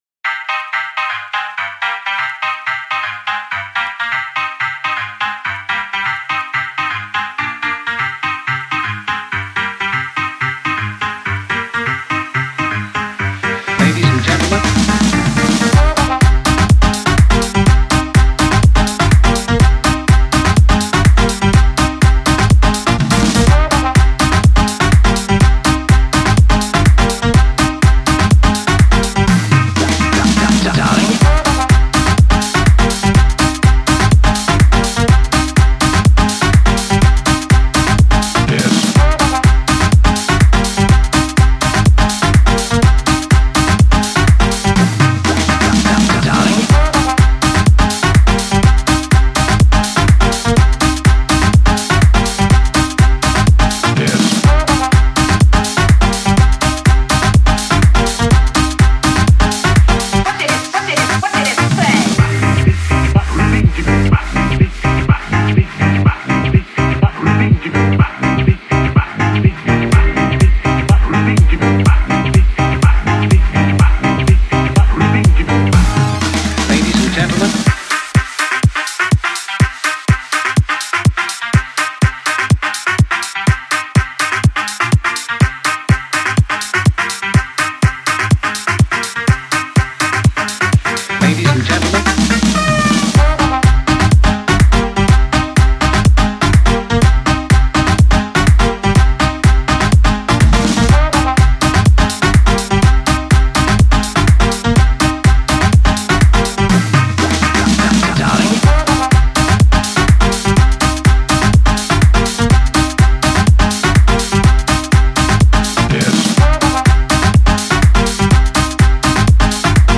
El sonido es aventurero y apasionado.